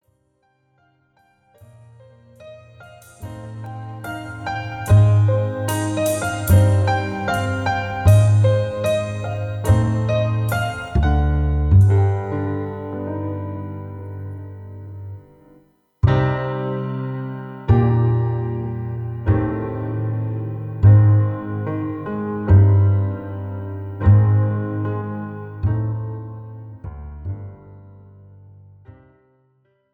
This is an instrumental backing track cover.
Key – D# / Eb
Without Backing Vocals
No Fade